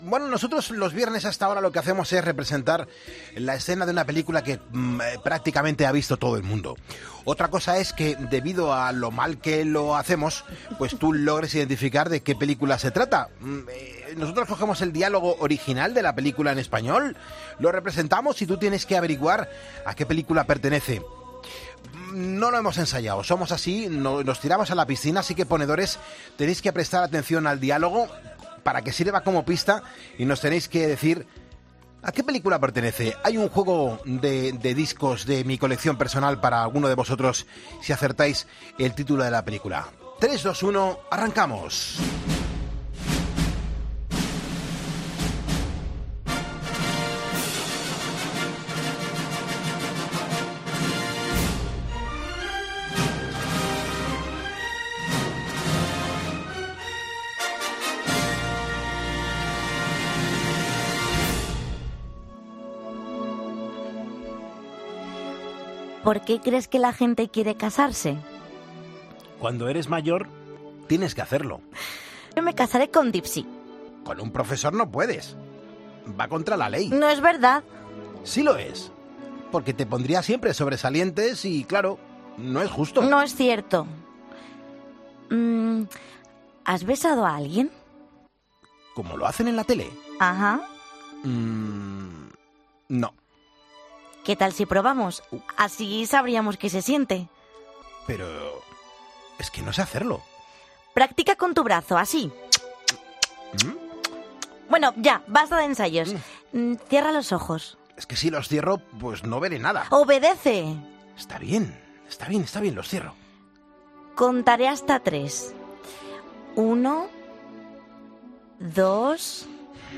Cada viernes tenemos una escena preparada para interpretar, bueno preparada... mejor dicho impresa, y el equipo de Poniendo Las Calles hace lo que puede.